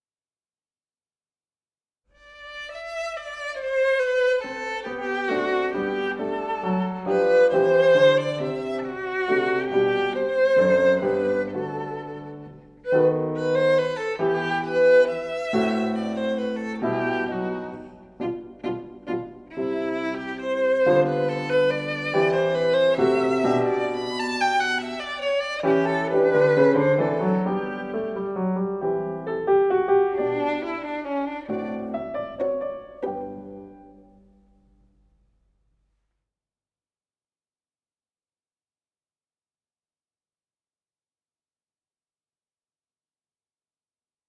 ソルフェージュ 聴音: コンサートホール・ヴァージョン(Vn.
コンサートホール・ヴァージョン(Vn. Pf.)